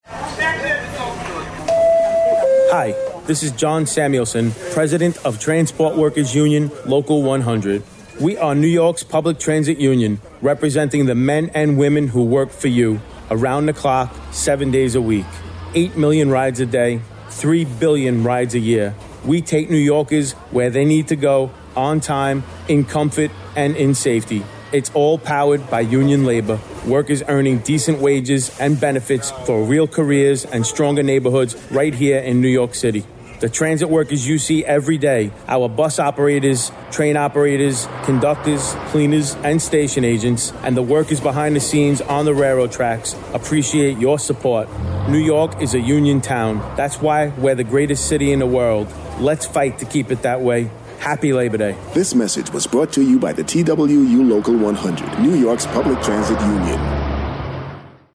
Hear his radio spot on WWRL, which is airing over the holiday week.